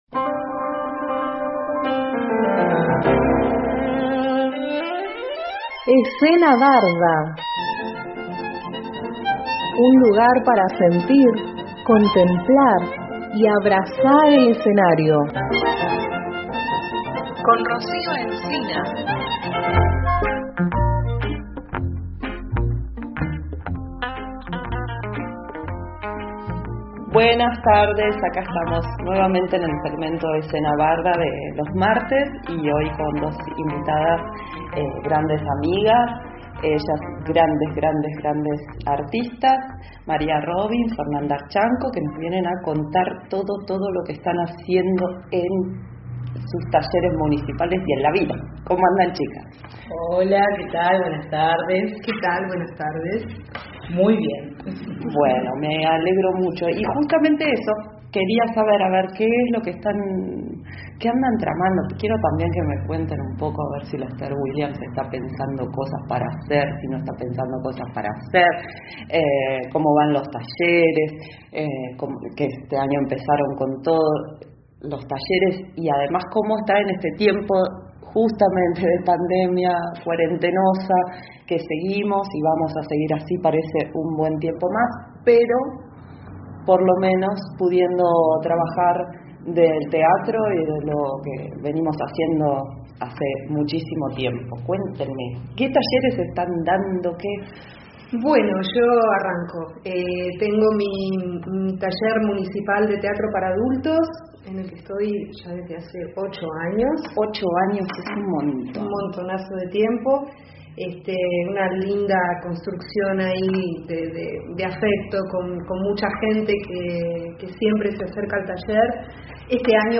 Columna de teatro